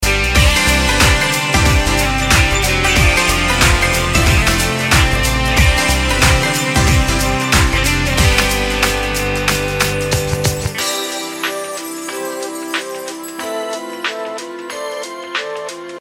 • Качество: 128, Stereo
позитивные
без слов
красивая мелодия
волшебные
Заводной, весёлый рингтон на смс или звонок